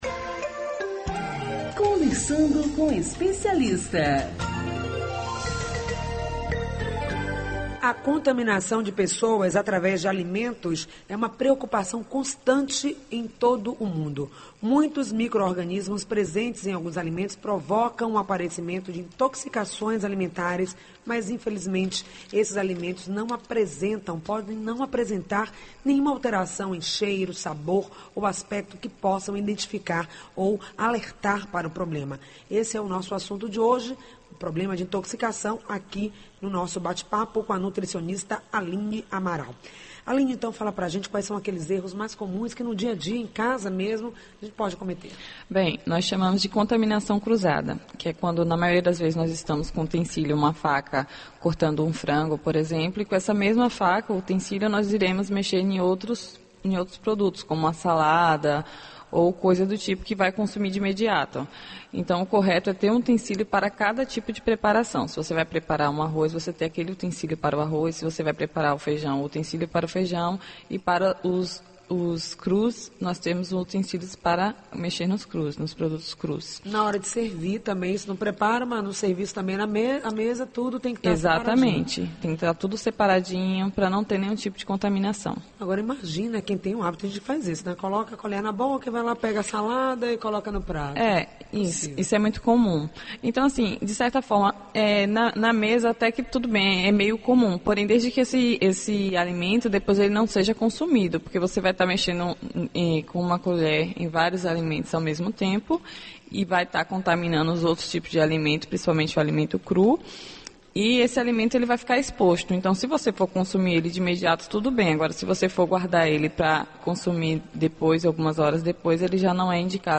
O programa Saúde No Ar vai ao ar de segunda à sexta-feira, das 10 às 11h, pela Rede Excelsior de comunicação: AM840, AM Recôncavo 1.460 e FM 106.1 e também pela Rádio Saúde no ar, (aplicativo gratuito).